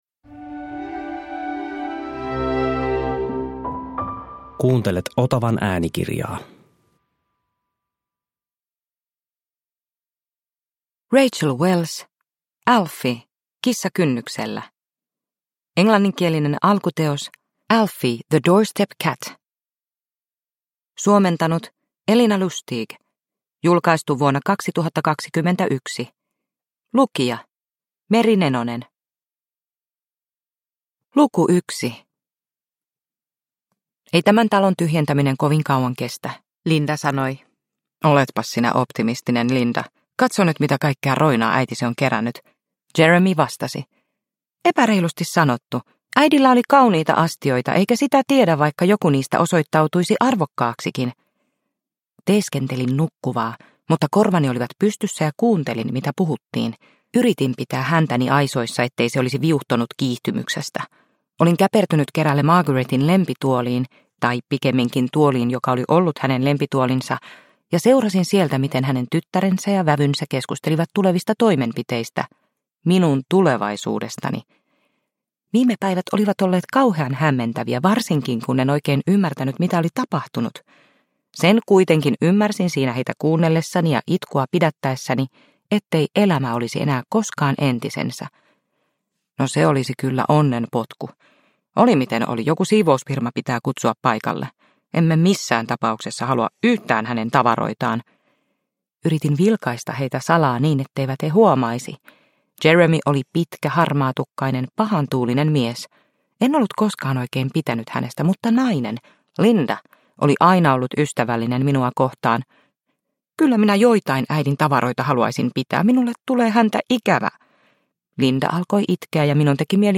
Alfie - kissa kynnyksellä – Ljudbok – Laddas ner
Alfie-kissan kertoma romaani aloittaa hellyttävän hyvän mielen kirjasarjan.